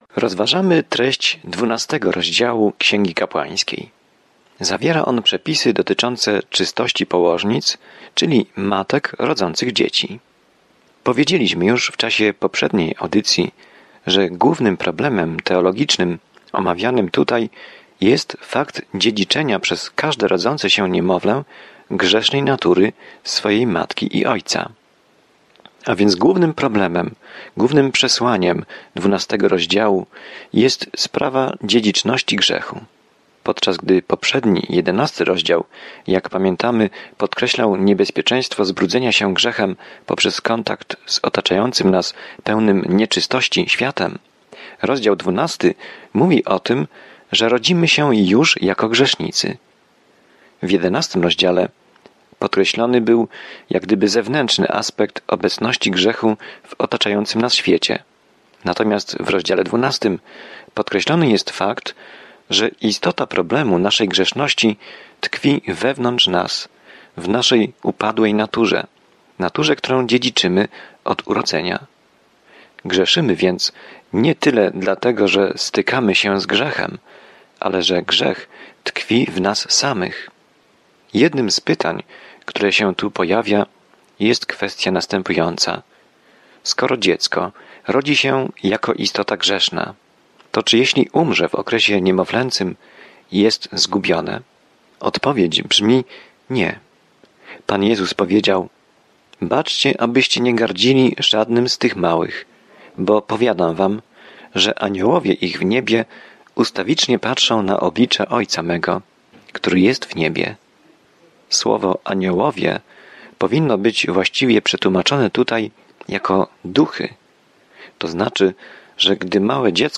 W oddawaniu czci, ofierze i czci Księga Kapłańska odpowiada na to pytanie starożytnego Izraela. Codziennie podróżuj przez Księgę Kapłańską, słuchając studium audio i czytając wybrane wersety słowa Bożego.